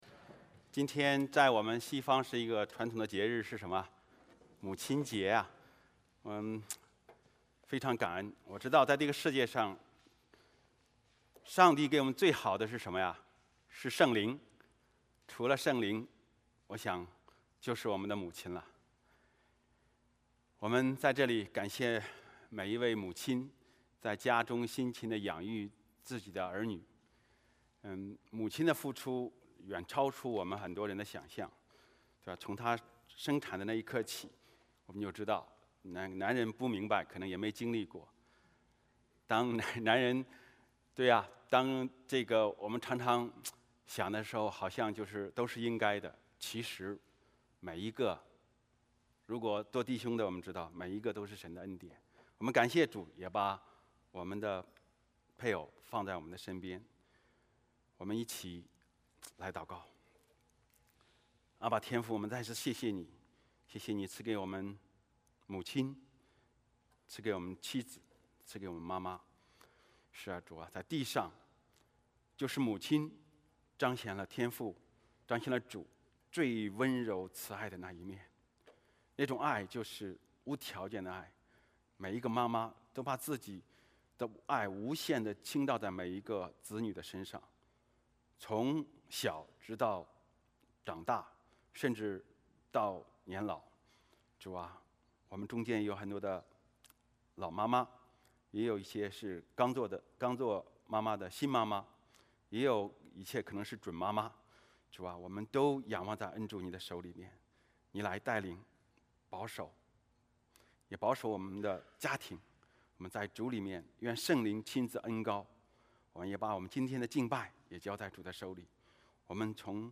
Passage: 使徒行传13:26-33 Service Type: 主日崇拜 欢迎大家加入我们的敬拜。